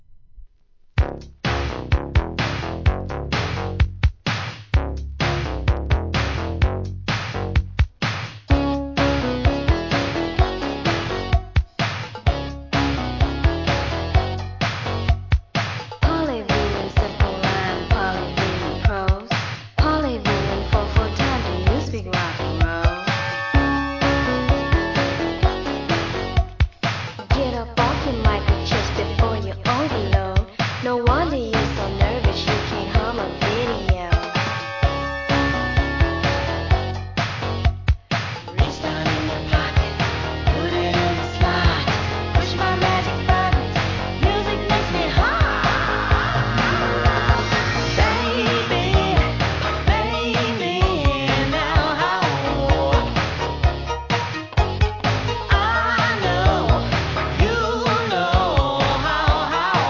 SOUL/FUNK/etc...
エレクトロFUNKナンバー！